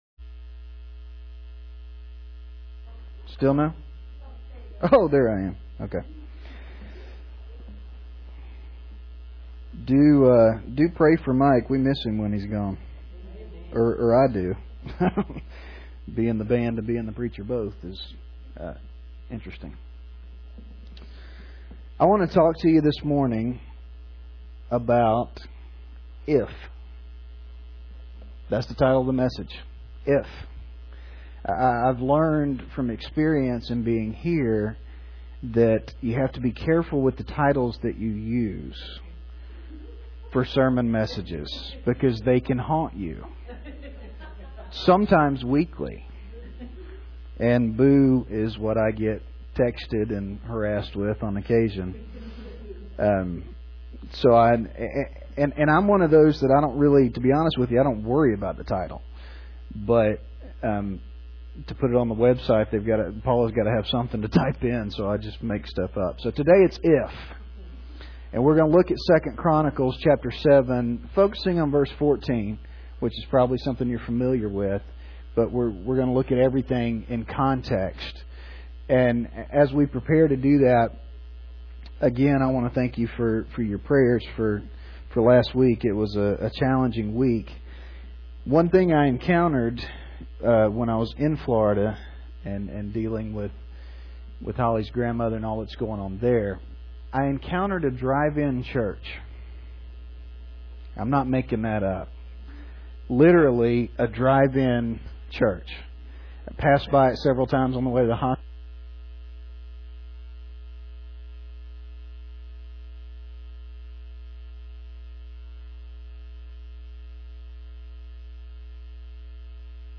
(Recording lost at 1:47, but resumes at 3:53)